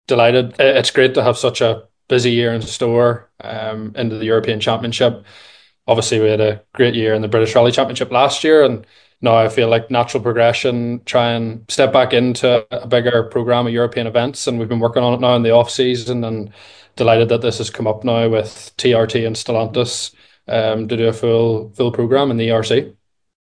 Speaking on the Krunching Gears Podcast